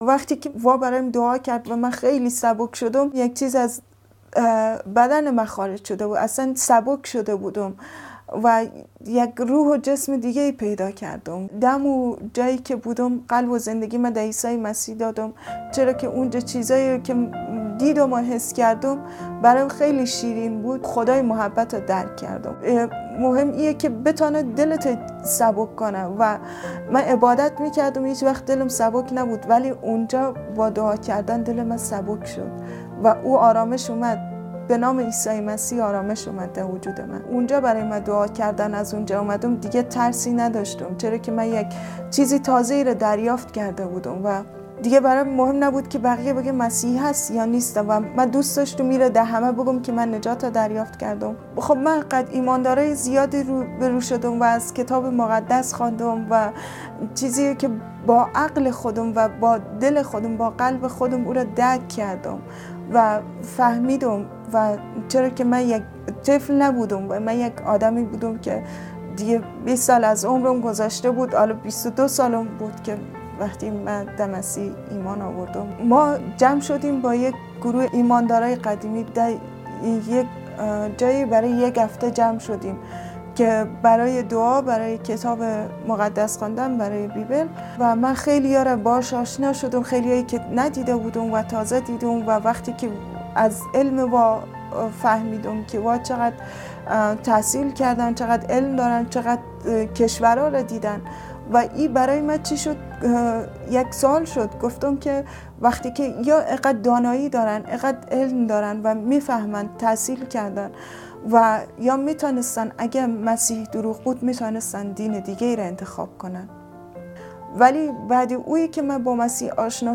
به این خواهر افغان گوش دهید وقتی که او در مورد زندگی تغییر یافته خود پس از ایمان به مسیح صحبت می‌کند!